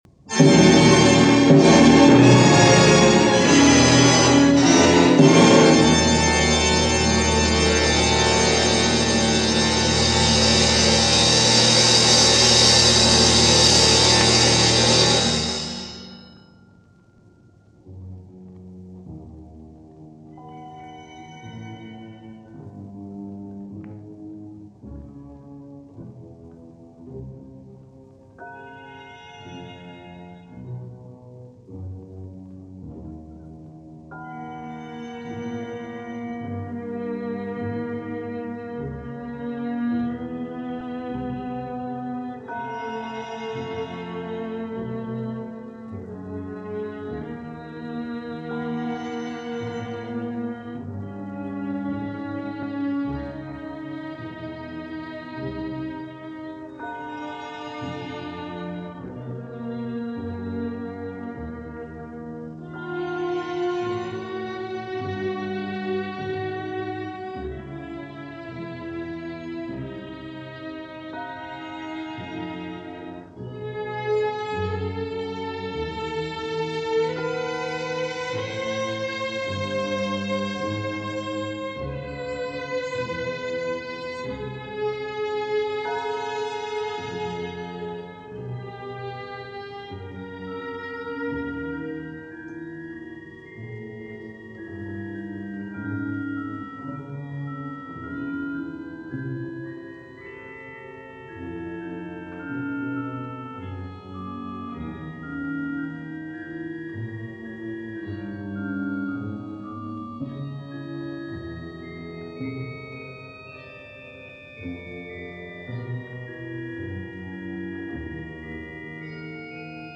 recorded December 1968 by ORTF Overseas Service.
Roger Calmel – Concerto For Organ, Strings and Percussion – Pierre Cochereau, Organ
ORTF Session – December, 1968